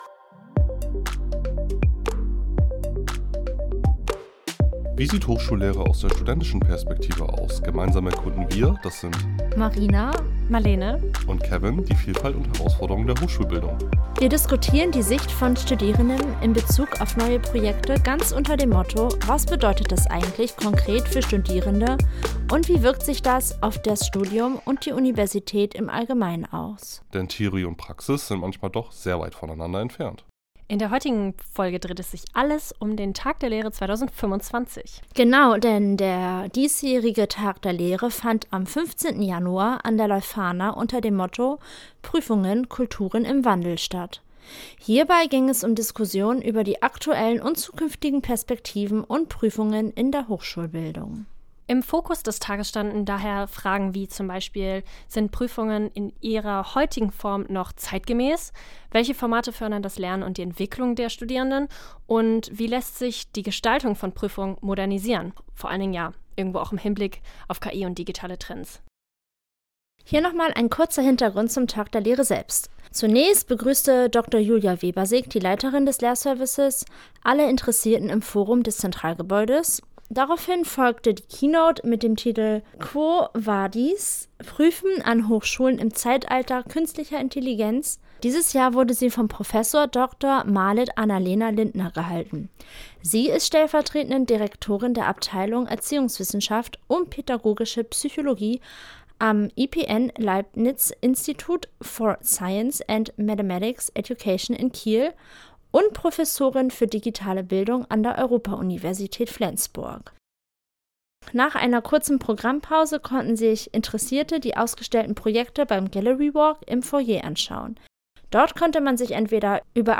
Auch dieses Jahr waren wir wieder live beim Tag der Lehre für euch unterwegs – doch diesmal mit einer besonderen Premiere: Unser eigener Stand beim Gallery Walk! Zwischen Vorträgen, Diskussionen und neuen Impulsen hatten wir die perfekte Gelegenheit, direkt mit euch ins Gespräch zu kommen....